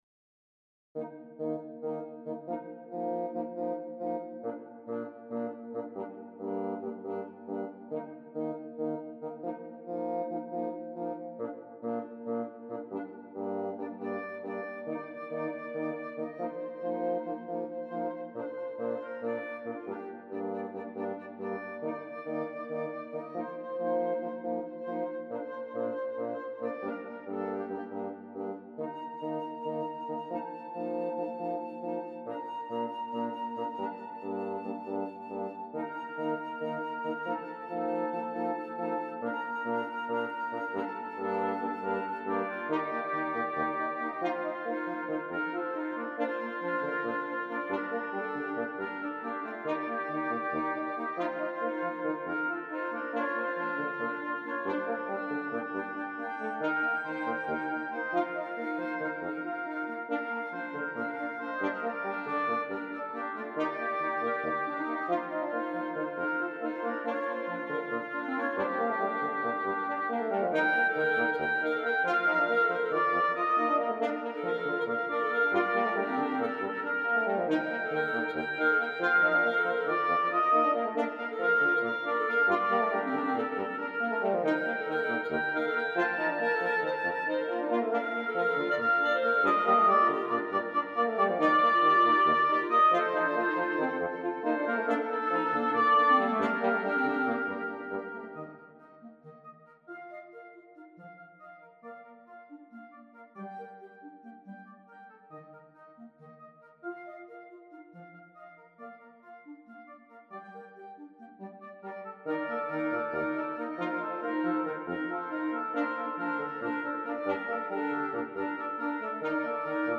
Gattung: für Holzbläserquintett
Besetzung: Ensemblemusik für 5 Holzbläser
Flöte, Oboe, Klarinette, Horn, Fagott